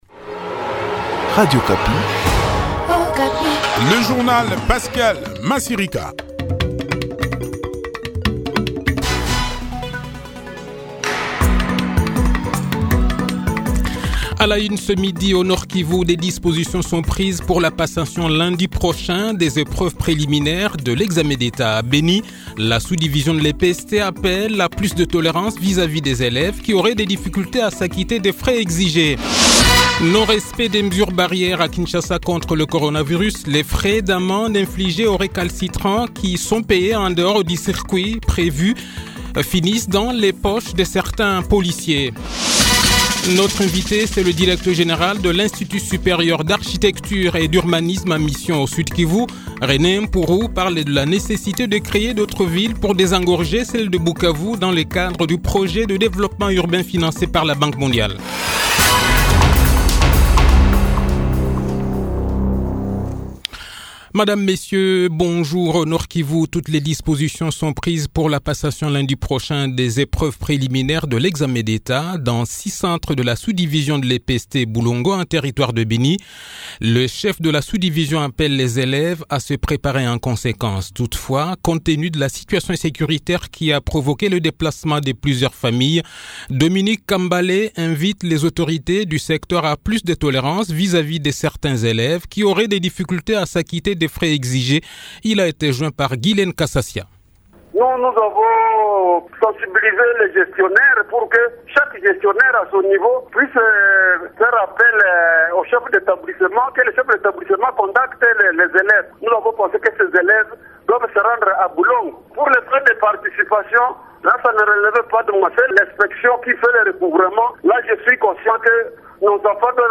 Le journal de 12 h, 8 Juillet 2021